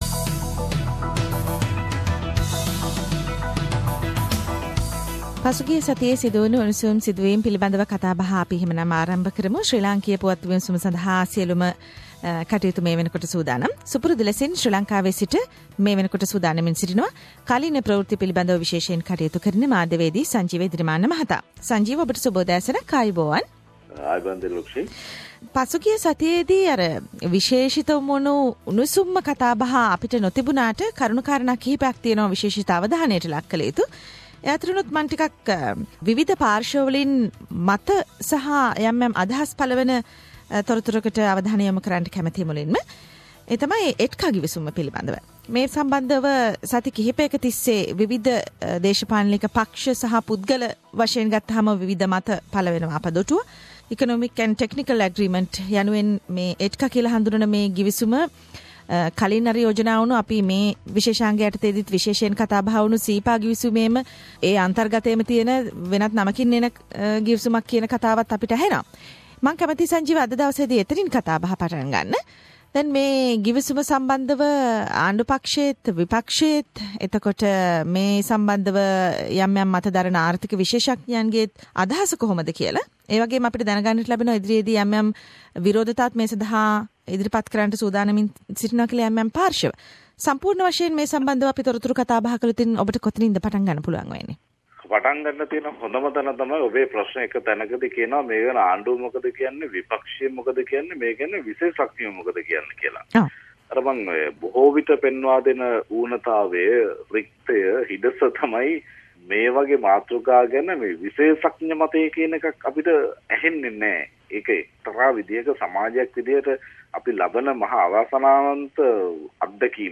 Weekly political highlights from Sri Lanka